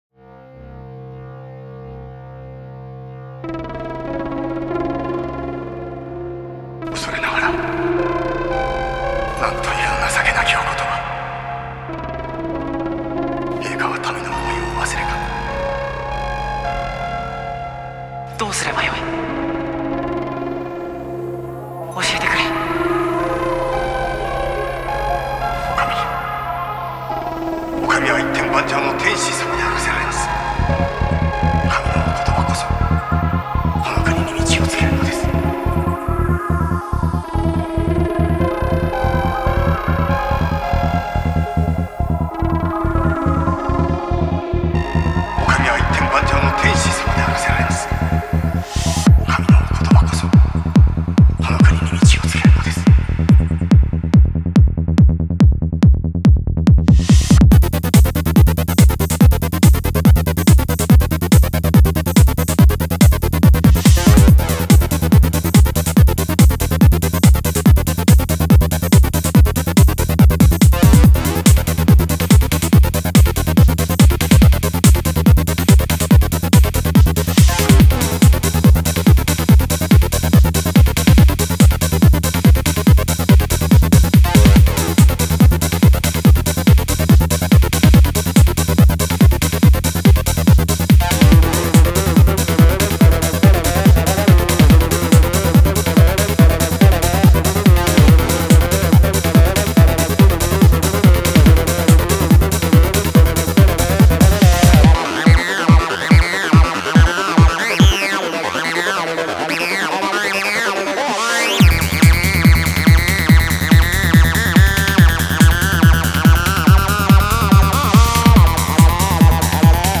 Файл в обменнике2 Myзыкa->Psy-trance, Full-on
Style: Goa Trance
Quality: 320 kbps / 44,100 Hz / Full Stereo